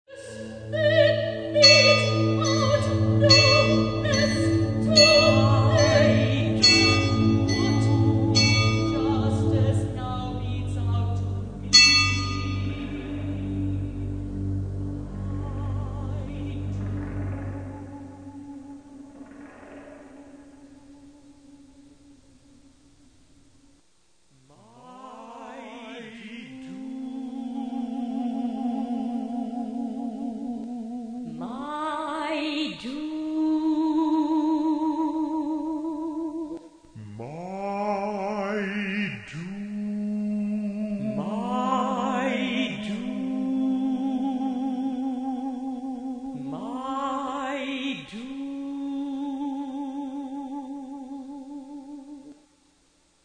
mixed with singer’s voice putting personal imprint on piece)
percussion